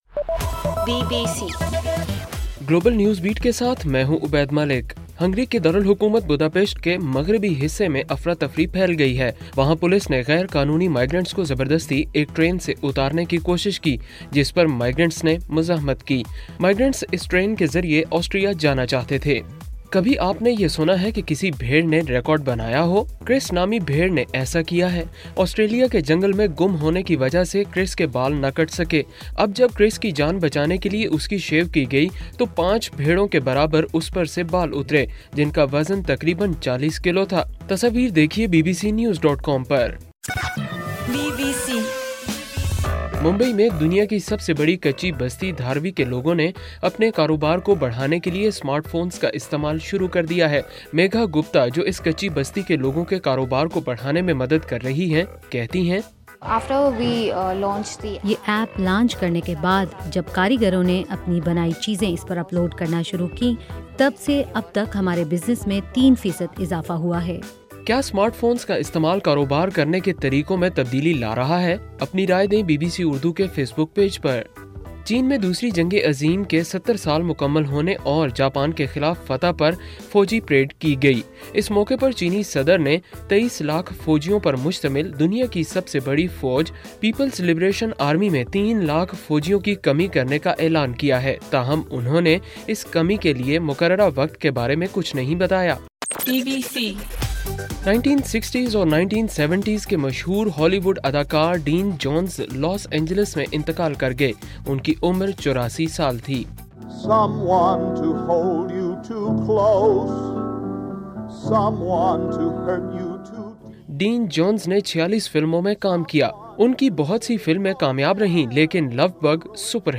ستمبر 3: رات 8 بجے کا گلوبل نیوز بیٹ بُلیٹن